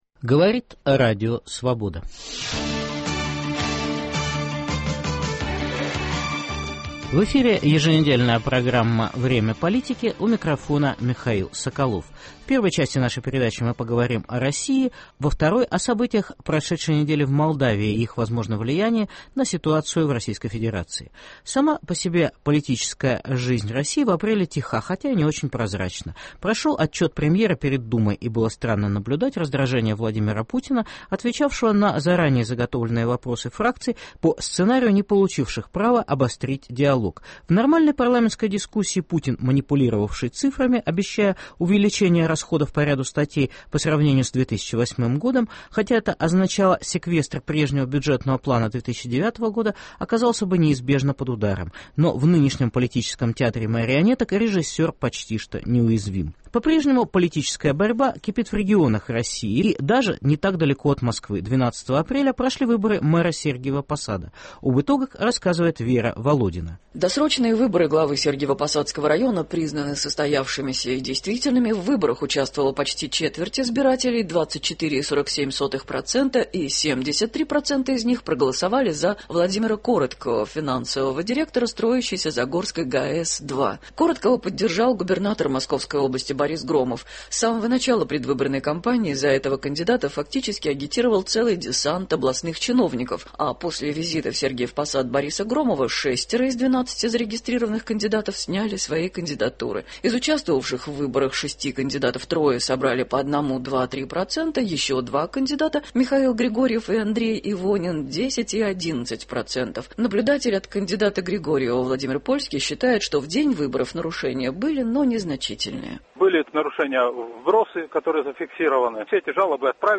Комментарии российских политиков и политологов. Где кипят политические страсти в России? Мэрские выборы: Сочи, Кисловодск, Сергиев Посад.